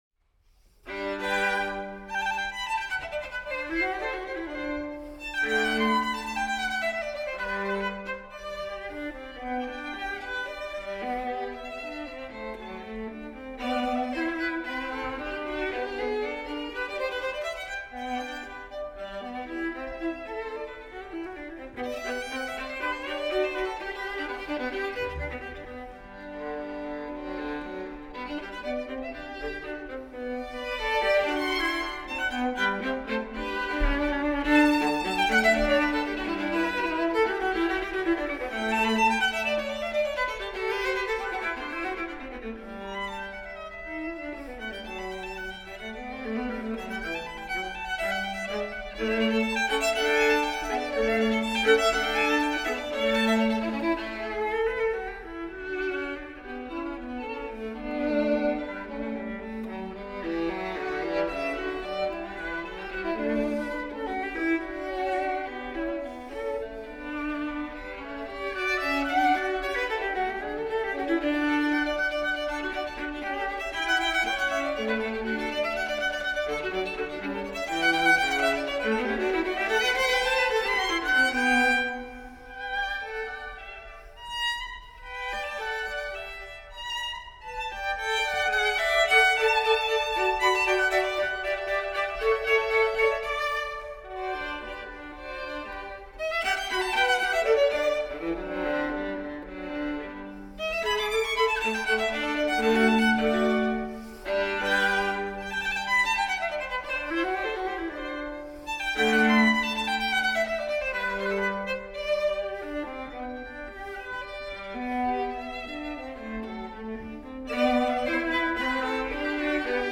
Violin-Viola Duet
Style: Classical
Audio: Boston - Isabella Stewart Gardner Museum
violin
viola
duo-for-violin-and-viola-k-423.mp3